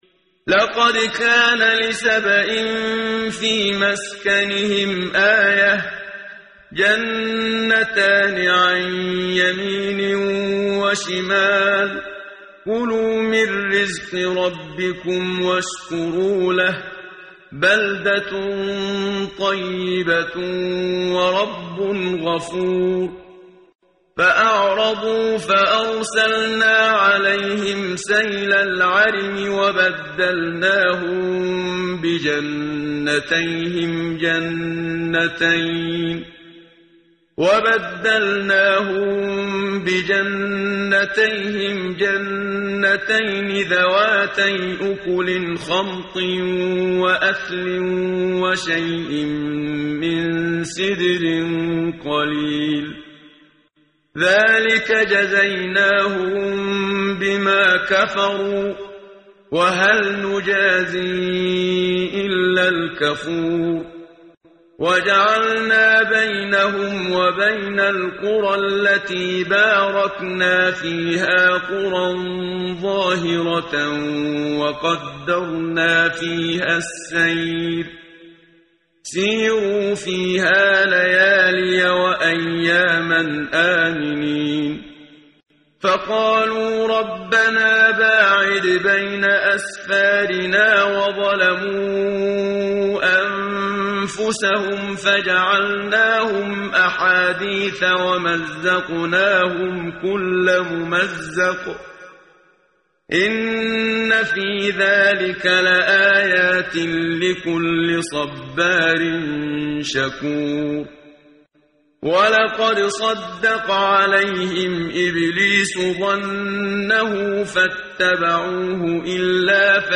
قرائت قرآن کریم ، صفحه 430 ، سوره مبارکه سبأ آیه 15 تا 22 با صدای استاد صدیق منشاوی.